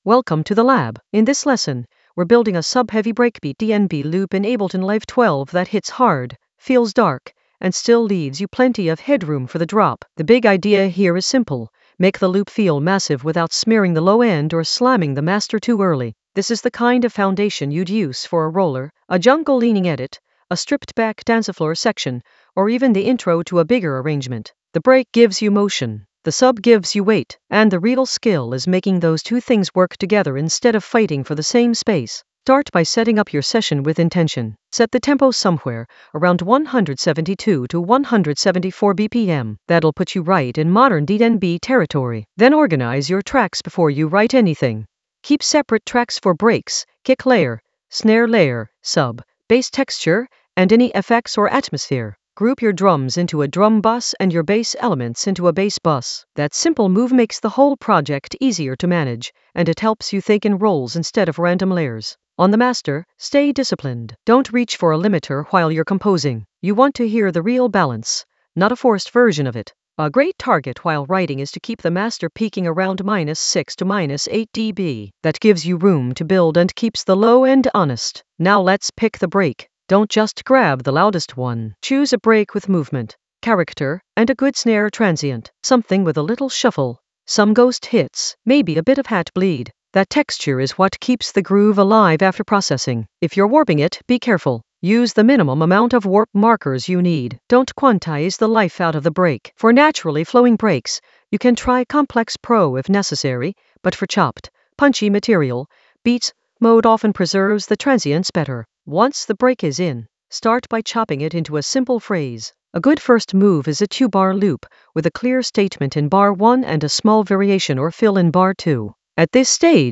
An AI-generated intermediate Ableton lesson focused on Subweight Ableton Live 12 breakbeat lab without losing headroom in the Composition area of drum and bass production.
Narrated lesson audio
The voice track includes the tutorial plus extra teacher commentary.